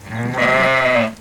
sheep.ogg